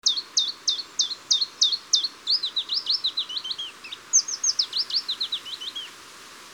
Sekalaulava uunilintu / A song switching Phylloscopus warbler
Äänite 3 Tiltalttimainen laulu muuttuu pajulintumaiseksi Recording 3 Chiffchaff-like song turns to Willow Warbler-like